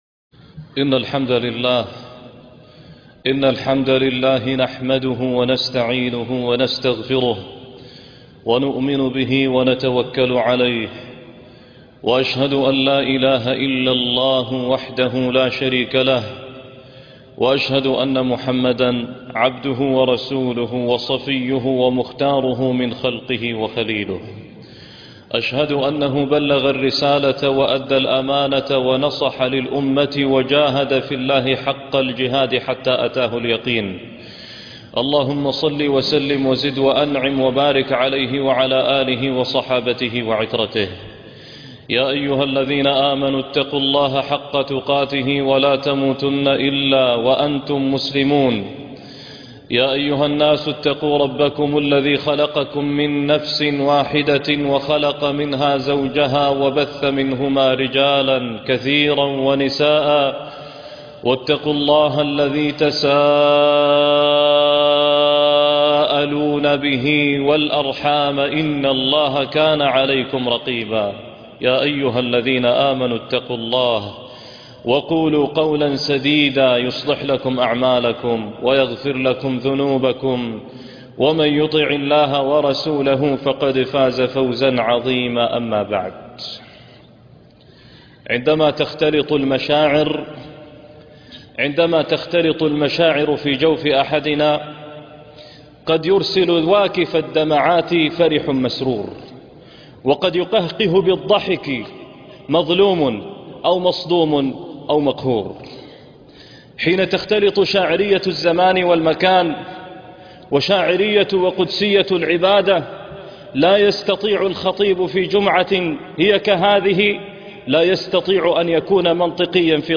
حج القلوب - خطبة الجمعة